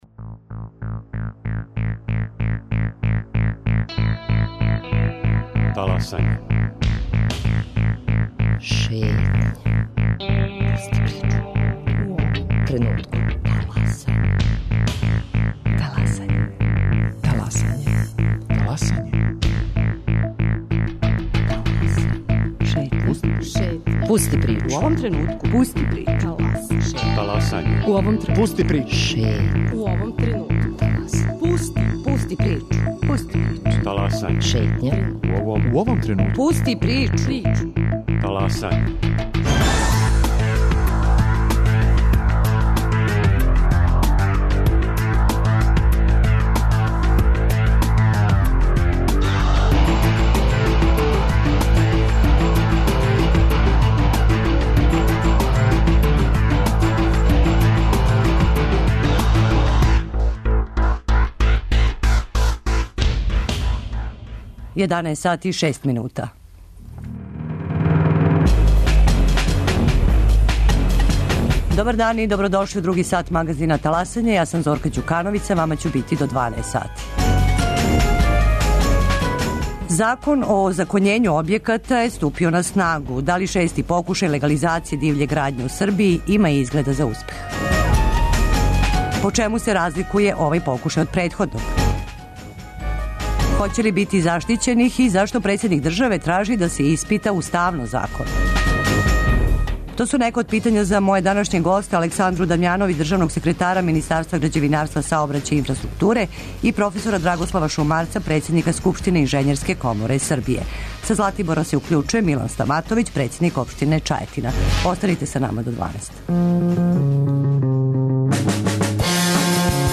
Са Златибора се укључује Милан Стаматовић, председник општине Чајетина.